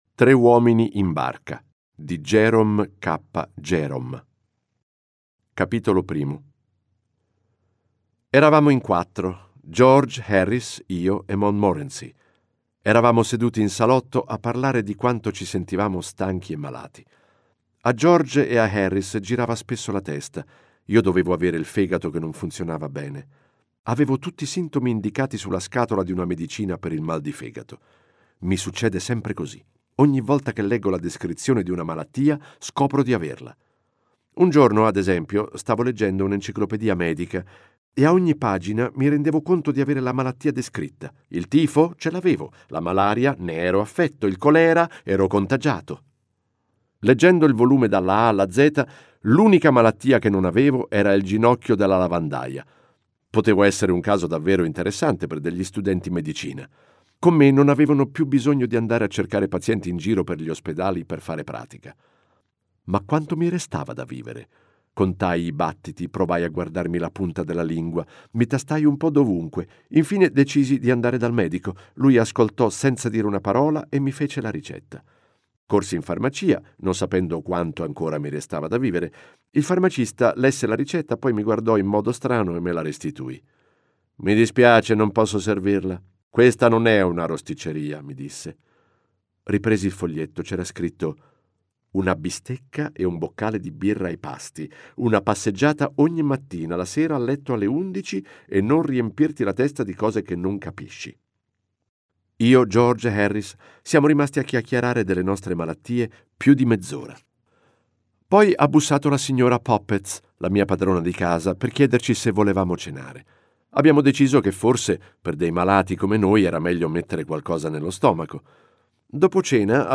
Letto da: Massimo Lopez